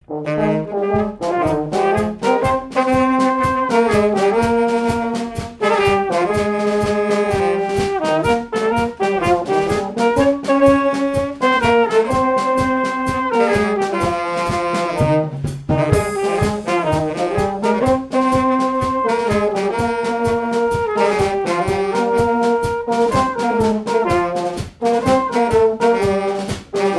danse : marche
Répertoire pour un bal et marches nuptiales
Pièce musicale inédite